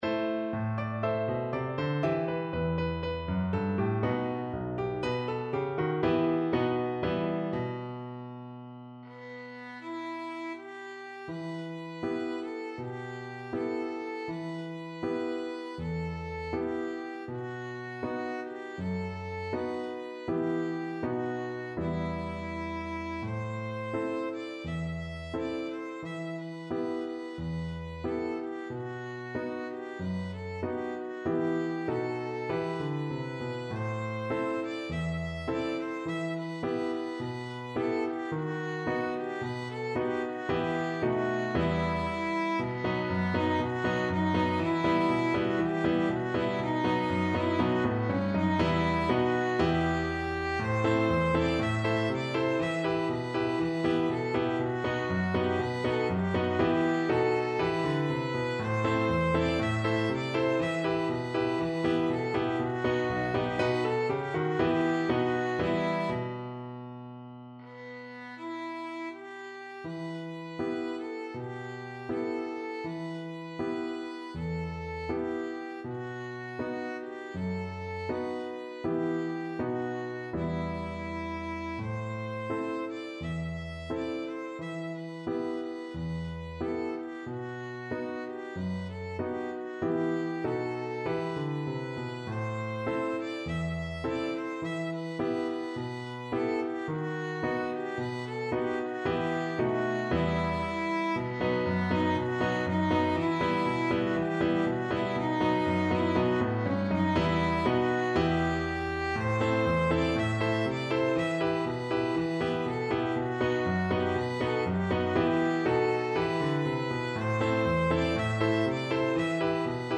4/4 (View more 4/4 Music)
Allegro =c.120 (View more music marked Allegro)
Violin  (View more Easy Violin Music)